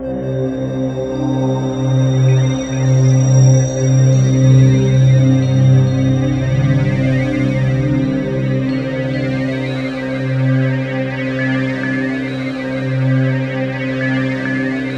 TUBULARC2.-R.wav